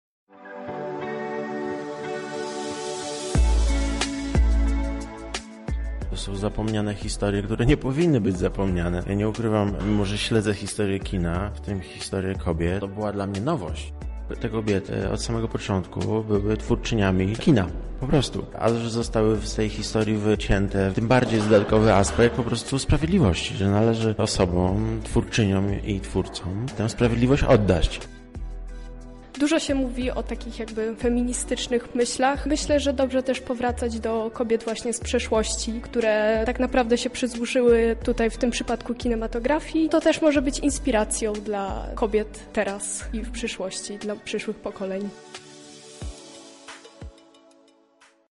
Obecnym spodobał się przekaz wykładu.